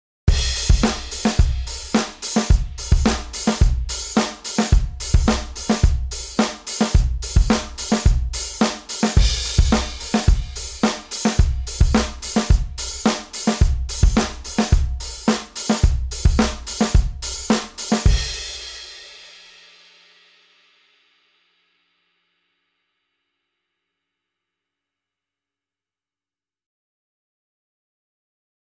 Sound Sample: Off Beat